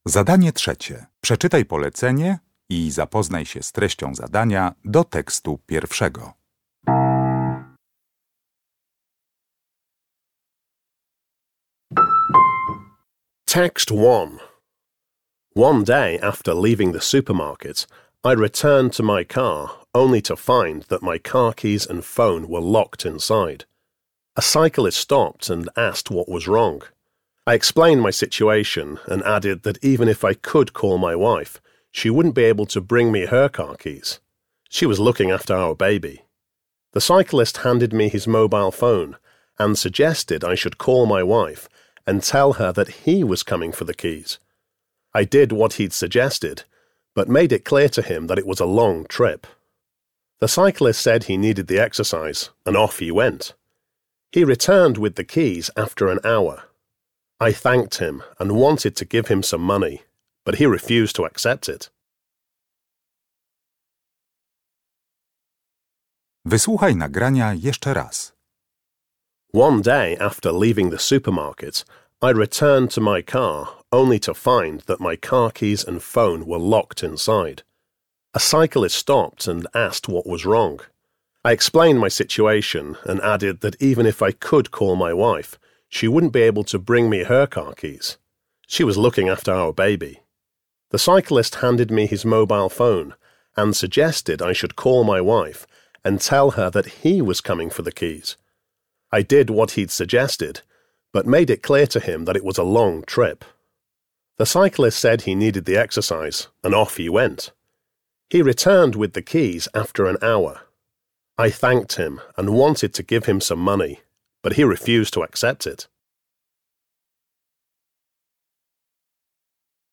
Uruchamiając odtwarzacz z oryginalnym nagraniem CKE usłyszysz dwukrotnie trzy teksty.